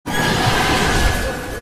Nightmare Huggy Jumpscare - Sound-Taste
Nightmare Huggy Jumpscare
nightmare-huggy-jumpscare.mp3